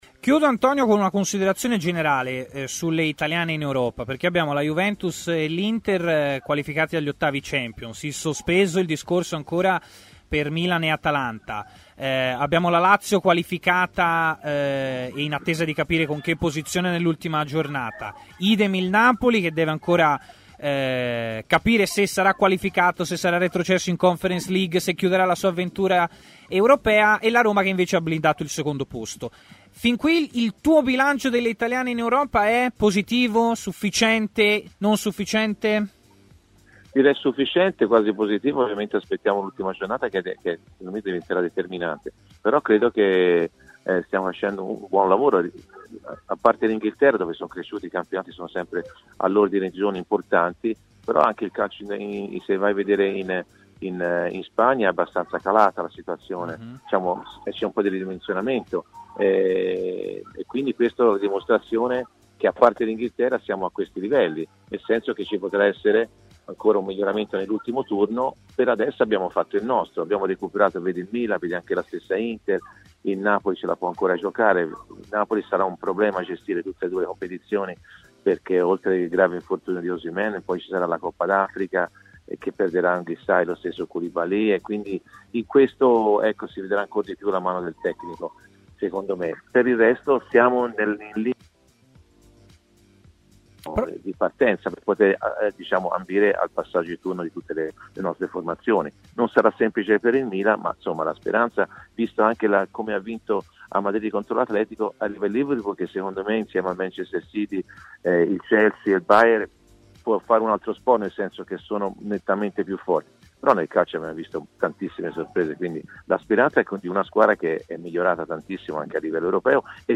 Antonio Di Gennaro è intervenuto a Stadio Aperto, trasmissione pomeridiana di TMW Radio, parlando dell'attualità calcistica.